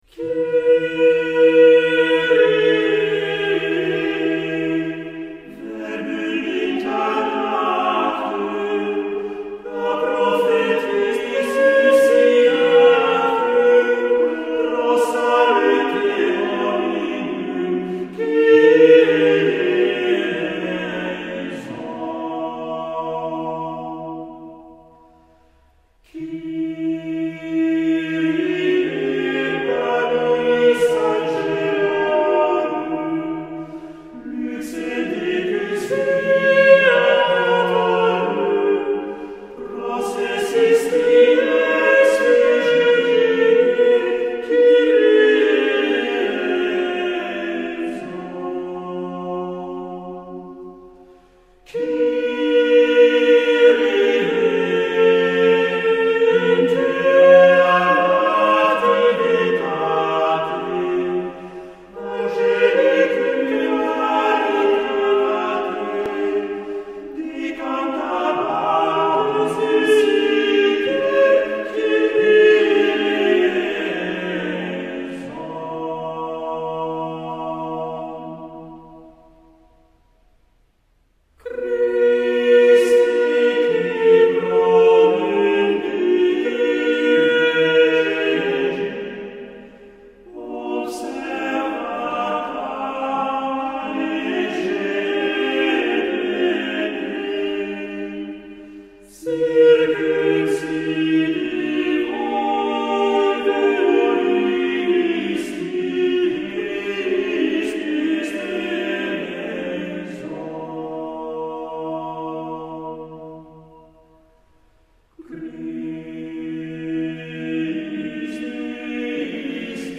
Cyclic mass
Cyclic mass 1430 - 1600 (Renaissance) Group: Mass Parts: Cyclic form Members: Motto mass , Cantus firmus mass Each of the movements shared a common musical theme, commonly a cantus firmus, thus making it a unified whole.